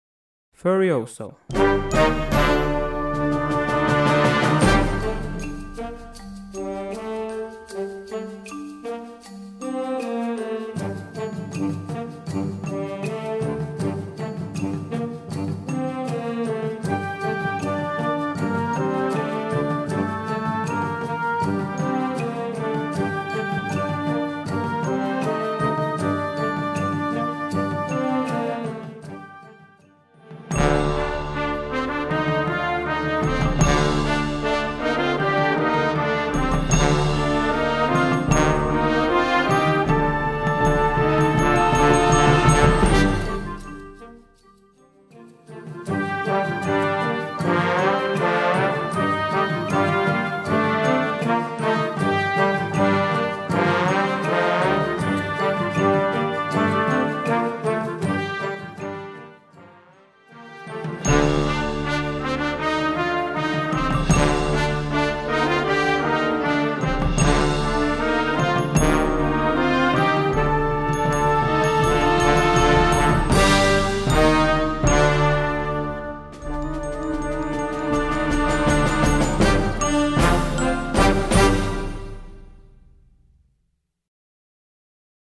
23 x 30,5 cm Besetzung: Blasorchester Tonprobe
dramatisches Stück.